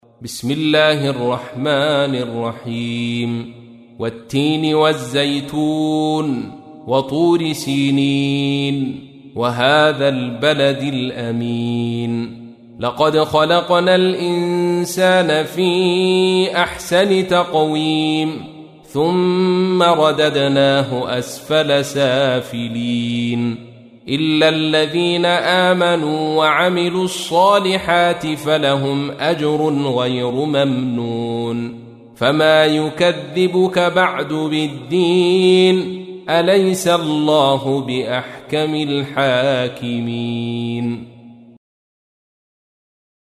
تحميل : 95. سورة التين / القارئ عبد الرشيد صوفي / القرآن الكريم / موقع يا حسين